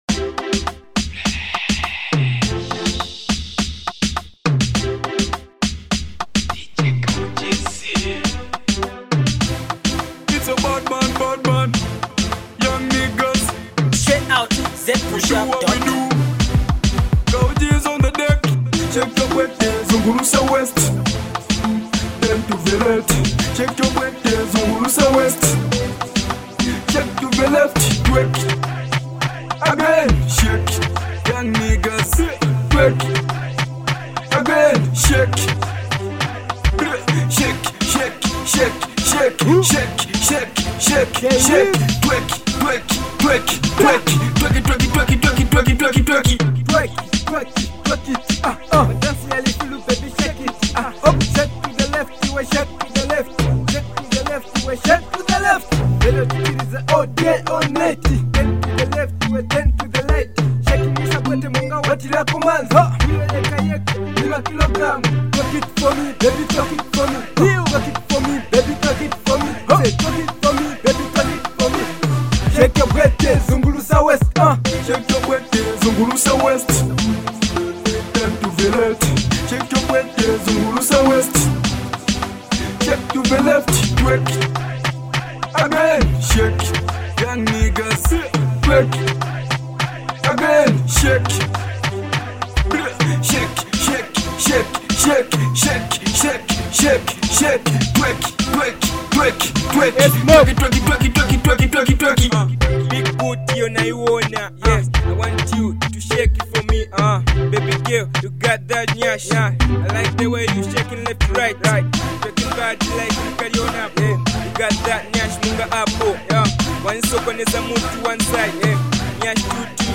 fresh and danceable tune